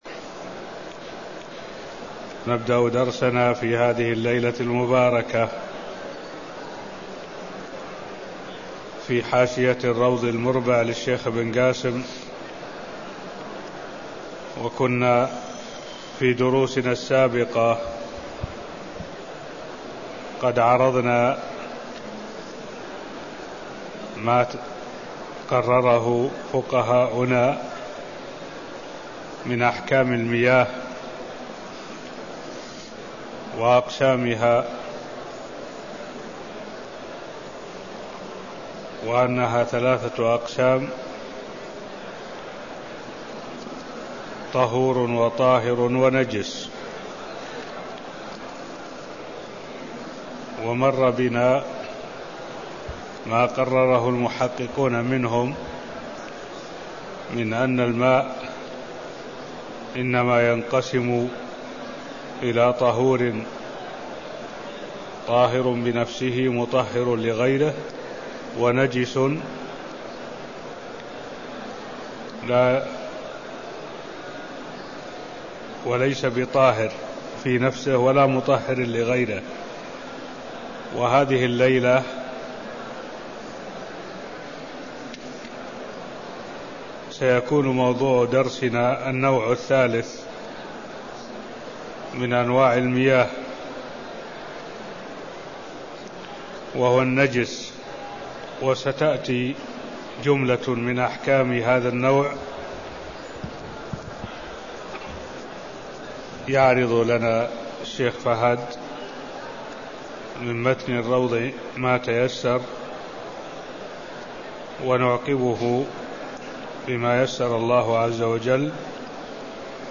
المكان: المسجد النبوي الشيخ: معالي الشيخ الدكتور صالح بن عبد الله العبود معالي الشيخ الدكتور صالح بن عبد الله العبود القسم الثالث من اقسام المياه (0016)